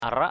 I.    /a-raʔ/